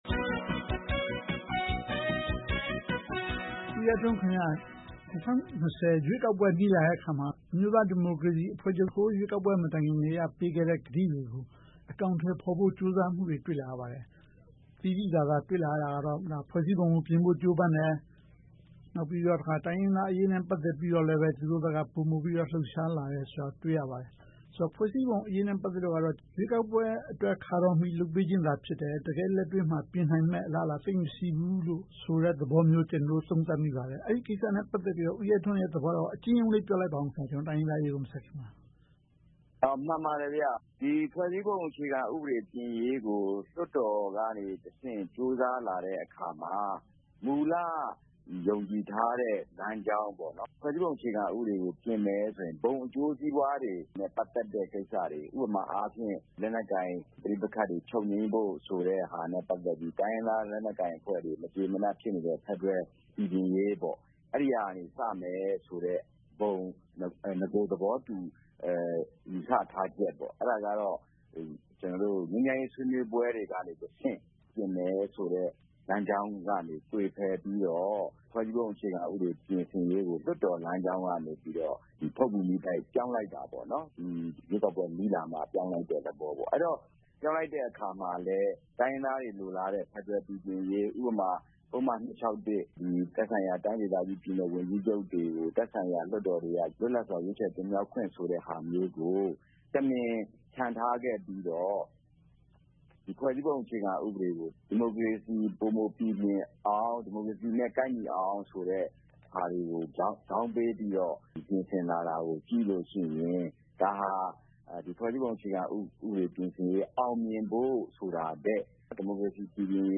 ဆွေးနွေးသုံးသပ်ထားပါတယ်။